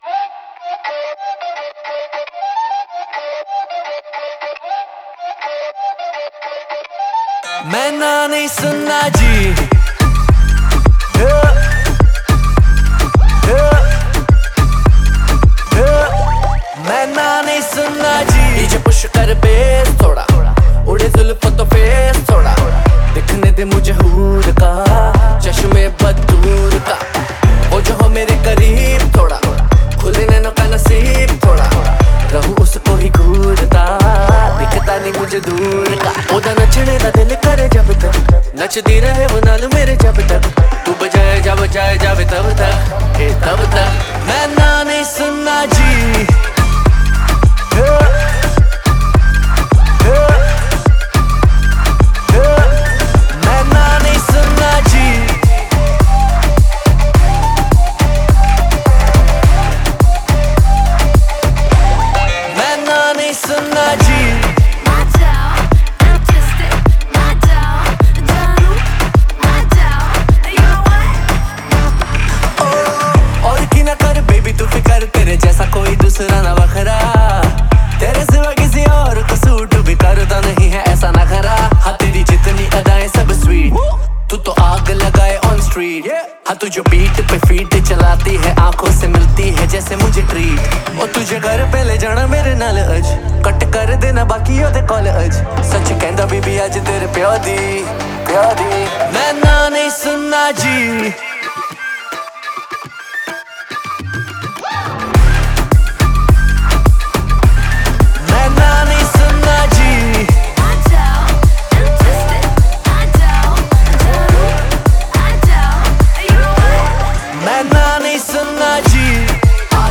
это зажигательный трек в жанре EDM и поп